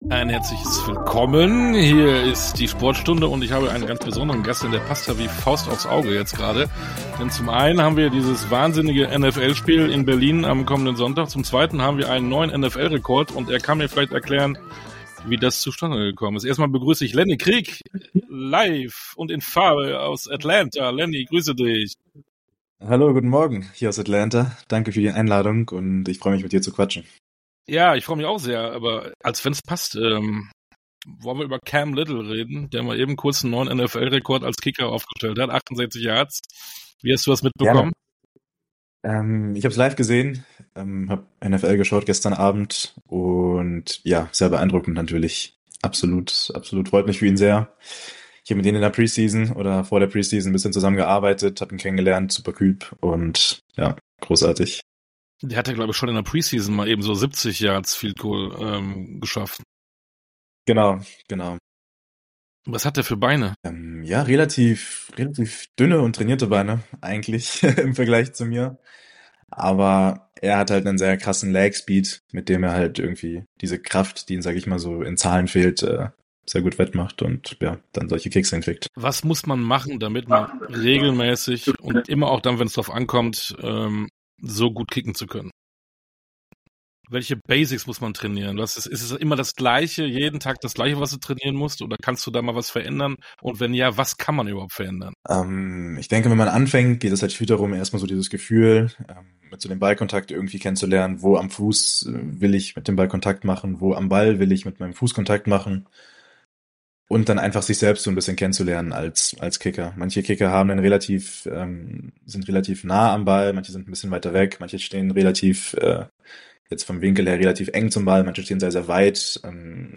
Ein ehrliches, inspirierendes Gespräch über Träume, Mut, harte Arbeit und das Vertrauen, dass sich alles lohnt, wenn man an sich glaubt.